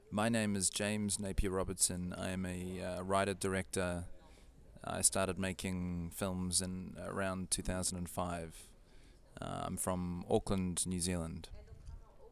James Napier Robertson introducing himself
James_Napier_Robertson_-_voice_-_en.flac